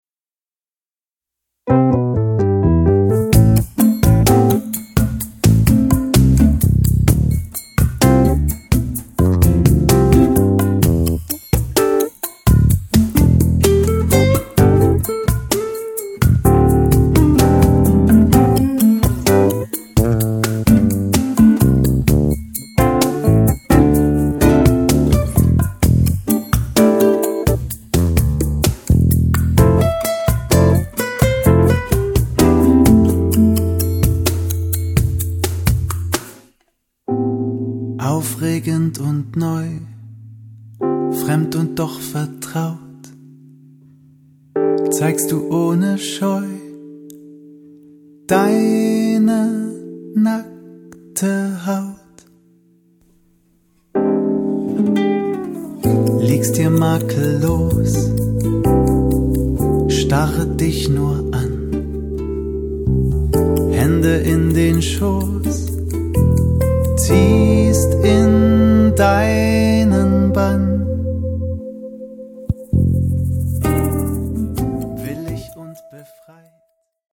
Gitarren
Percussion